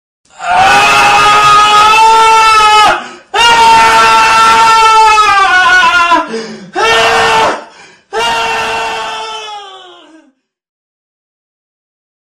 Efek suara Orang Teriak
Kategori: Suara manusia
Keterangan: Efek suara meme orang teriak menjadi viral di media sosial, banyak digunakan dalam video untuk menambah dramatisasi.
efek-suara-orang-teriak-id-www_tiengdong_com.mp3